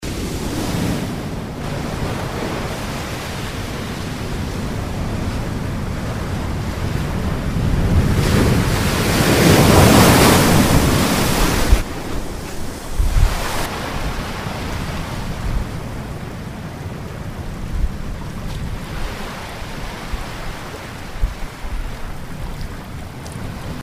Mareggiata a Varigotti (Capodanno 2010)
mareggiata con onda arrabbiata.mp3